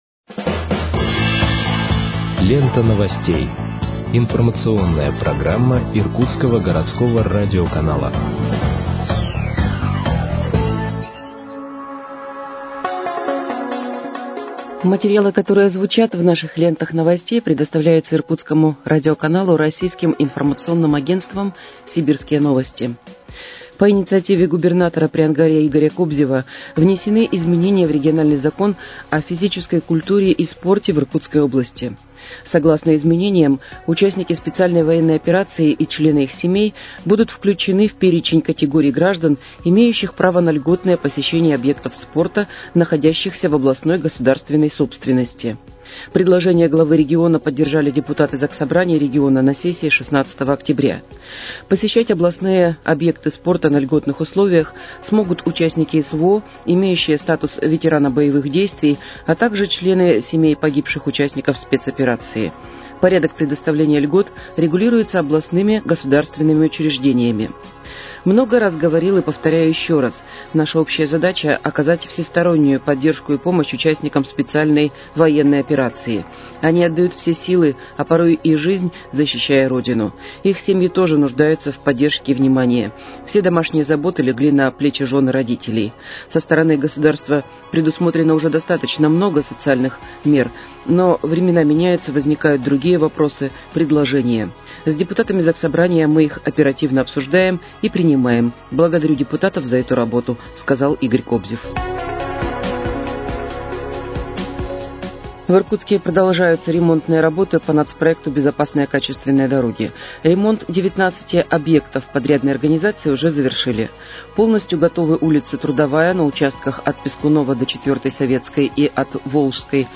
Выпуск новостей в подкастах газеты «Иркутск» от 22.10.2024 № 1